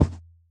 Minecraft Version Minecraft Version snapshot Latest Release | Latest Snapshot snapshot / assets / minecraft / sounds / mob / camel / step5.ogg Compare With Compare With Latest Release | Latest Snapshot